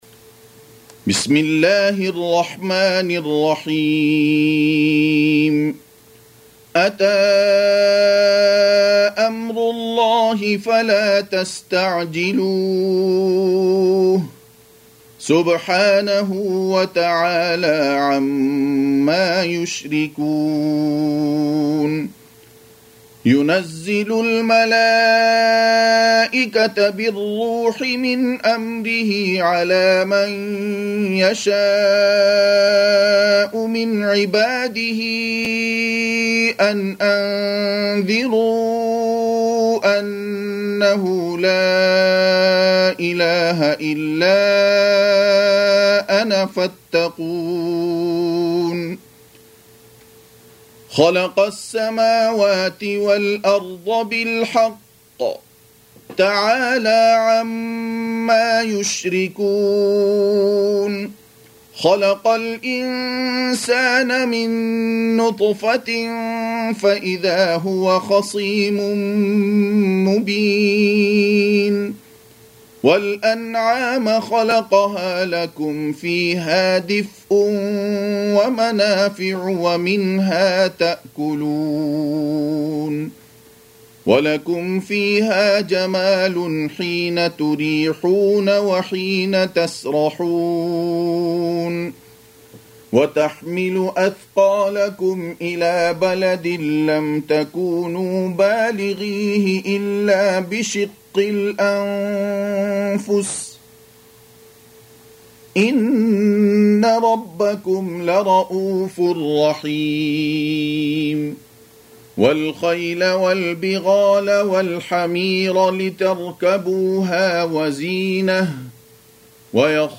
Surah Repeating تكرار السورة Download Surah حمّل السورة Reciting Murattalah Audio for 16. Surah An-Nahl سورة النحل N.B *Surah Includes Al-Basmalah Reciters Sequents تتابع التلاوات Reciters Repeats تكرار التلاوات